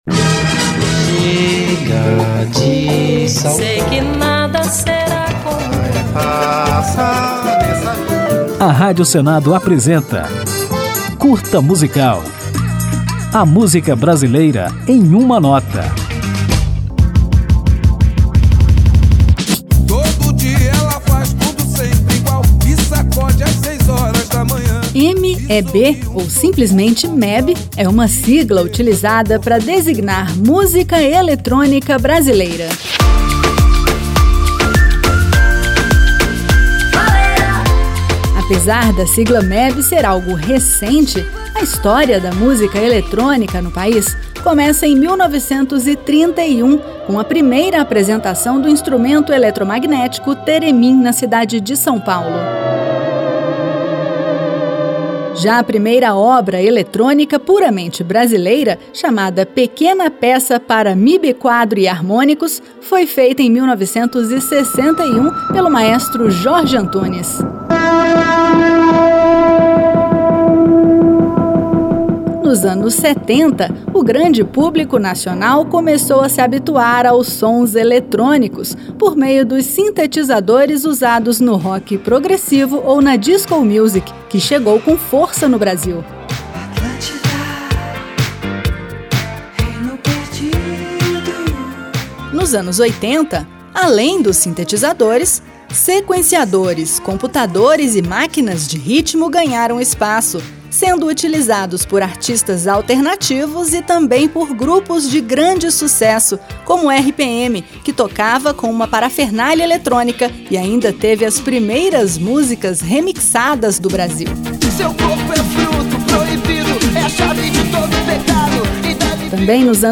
Para ilustrar a evolução da MEB, ouviremos um dos primeiros projetos de Dance Music do Brasil, a banda Que Fim Levou Robin?, com a música de mesmo nome.